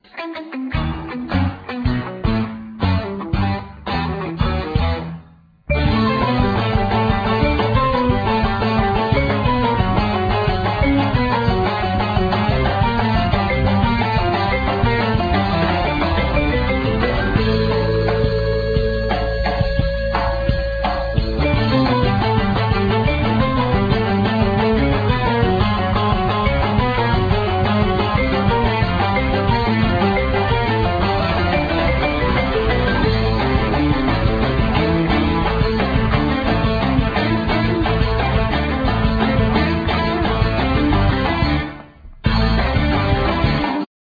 Guitar,Bouzouki,Veena,Voice
Drums,Percussion,Marimba,Voice
Keyboards,Accordion,Melodica,Voice
Bass,Voice